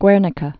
(gwârnĭ-kə, gĕr-nēkä)